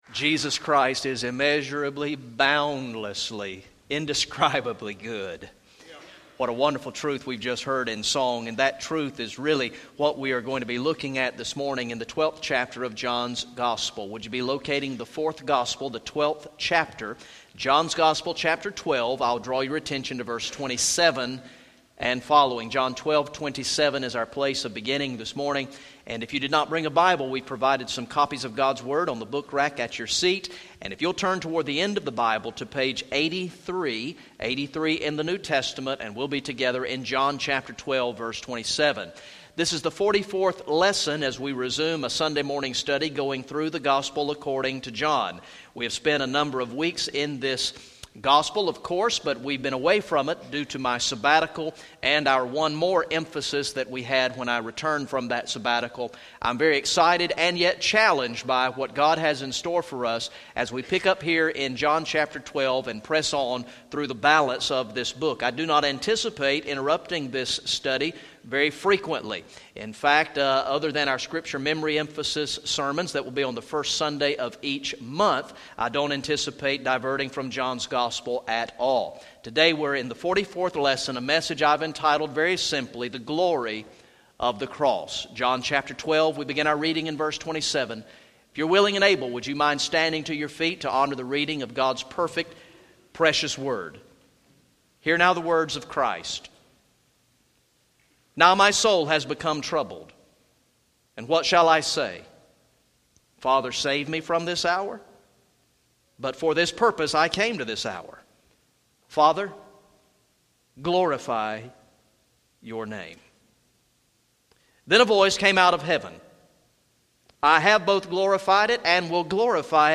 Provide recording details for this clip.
Message #44from the sermon series through the gospel of John entitled "I Believe" Recorded in the morning worship service on Sunday, October 11, 2015